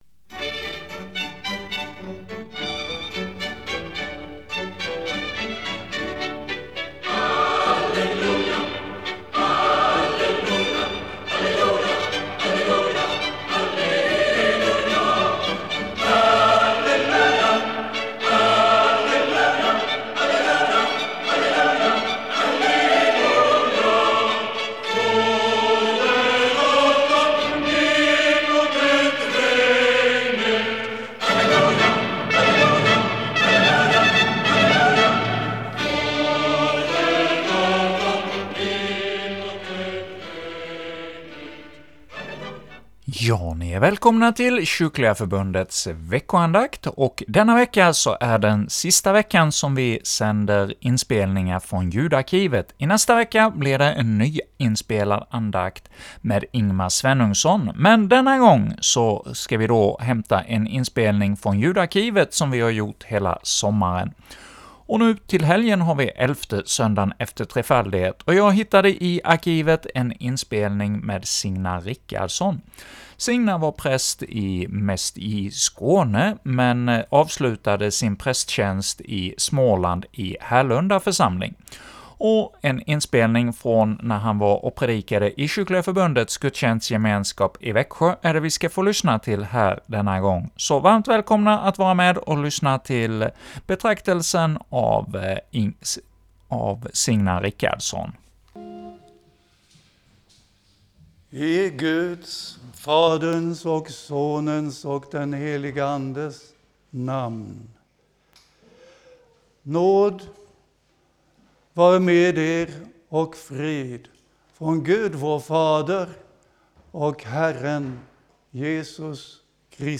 andakt